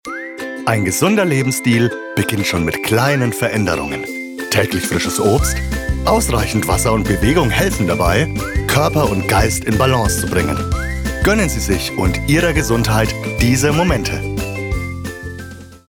Werbung: „Gesunder Lebensstil“